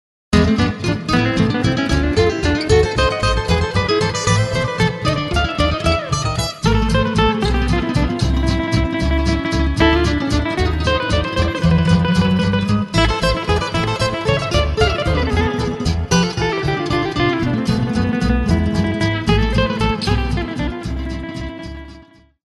10 instrumentala